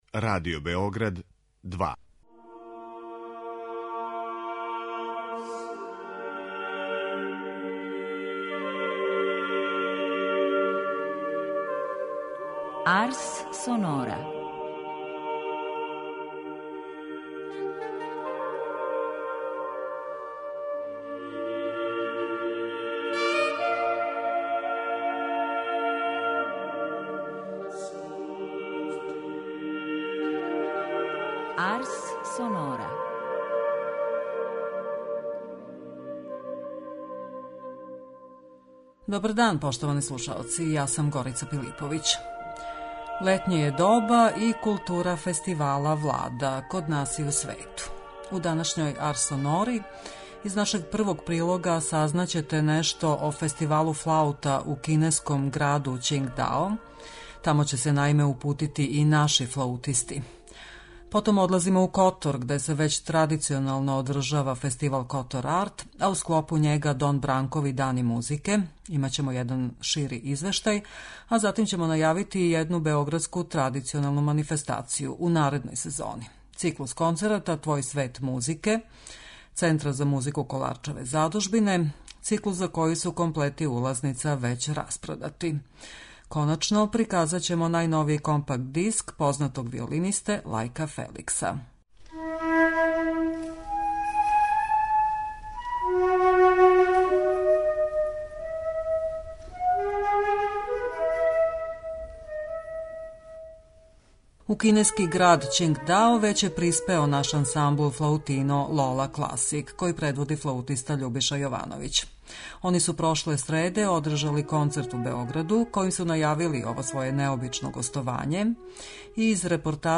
ПРЕГЛЕД МУЗИЧКЕ НЕДЕЉЕ